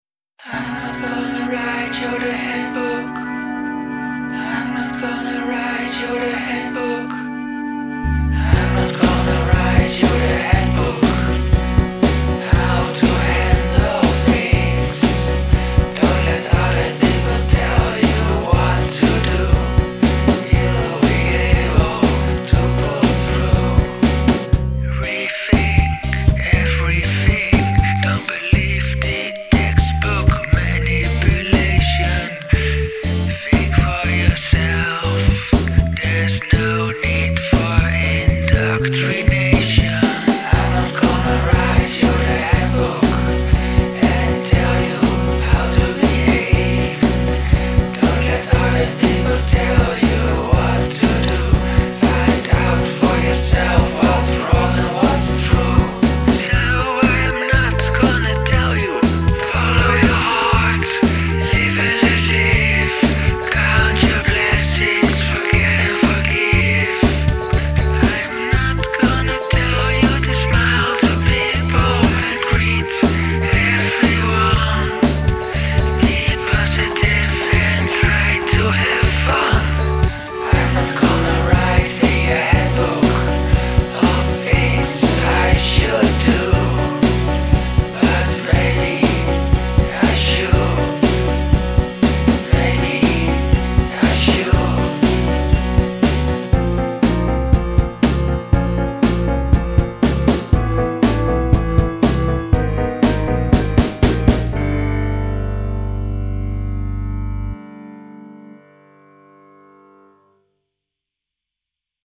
eerie and enchanting, haunted house vibes.
I like your use of typewriter sounds.